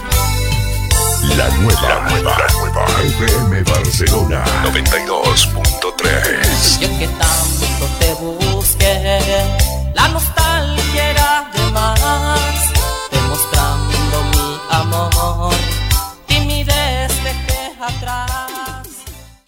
Indicatiu de la ràdio